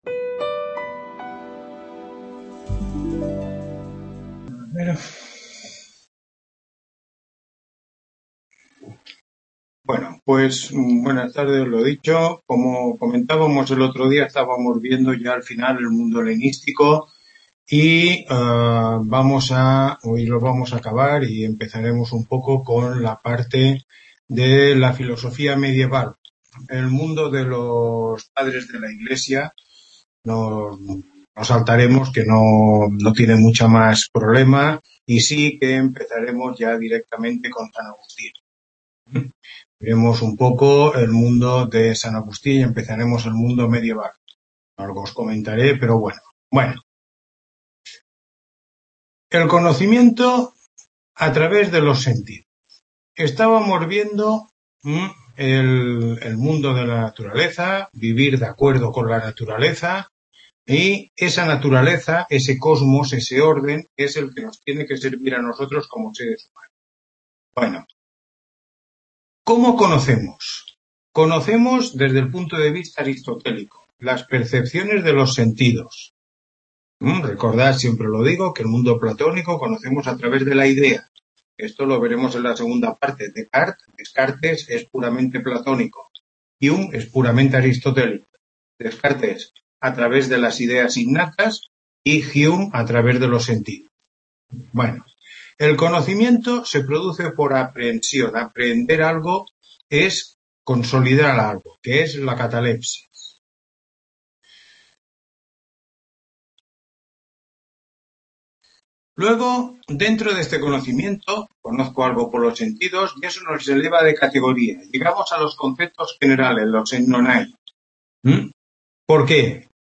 Tutoría 8